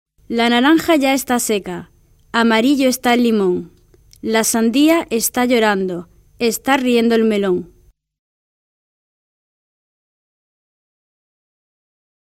‘La naranja ya esta seca’, an audio rhyme.